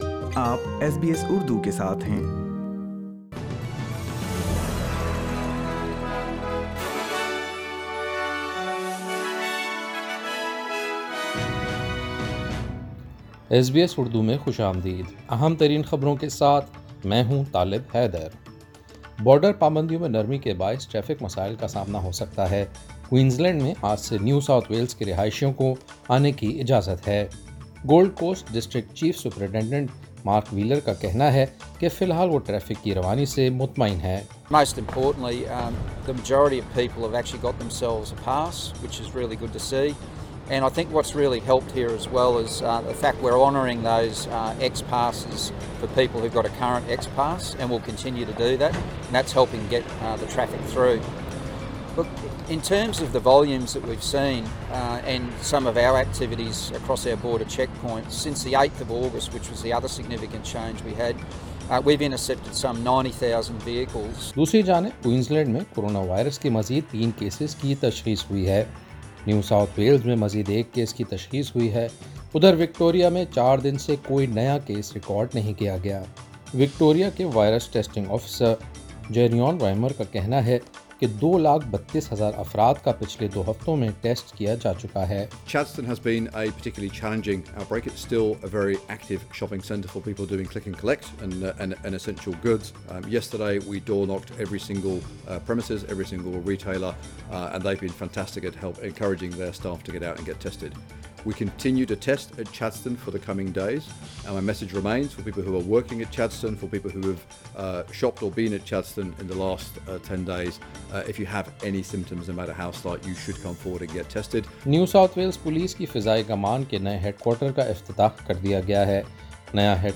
ایس بی ایس اردو خبریں 3 نومبر 2020